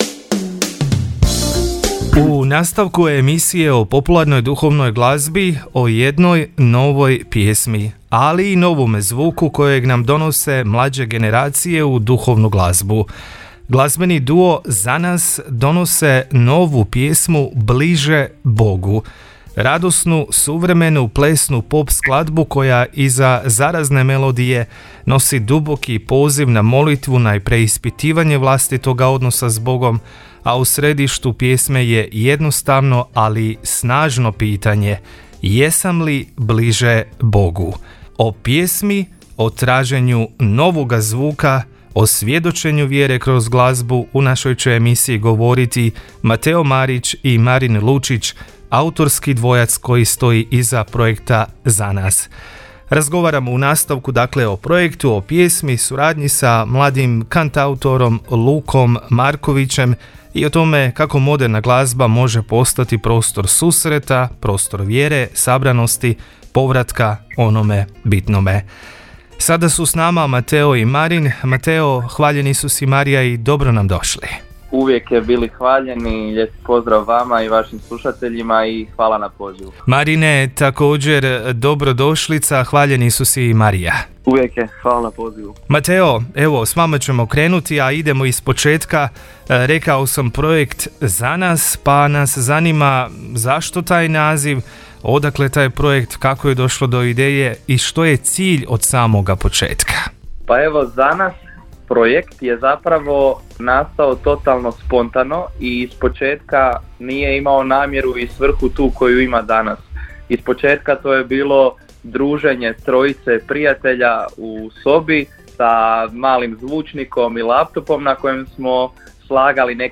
bili su gosti emisije o duhovnoj glazbi Popsacro val